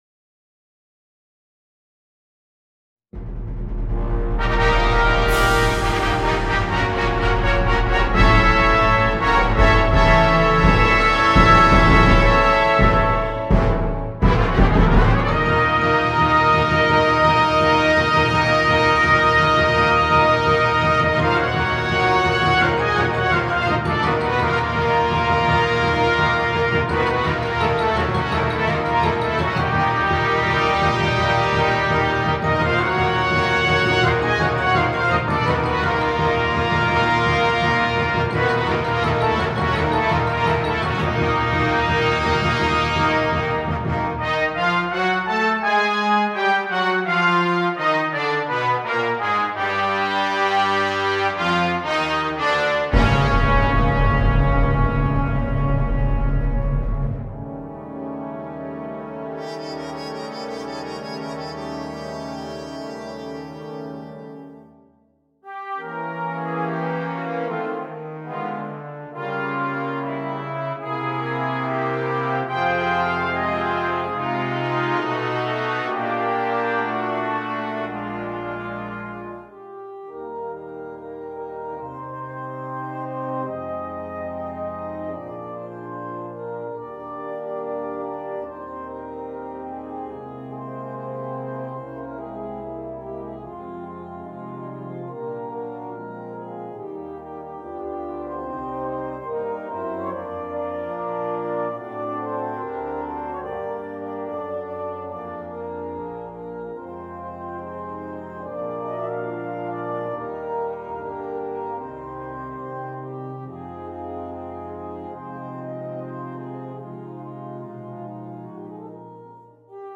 Besetzung: Flugel Horn Solo & Brass Band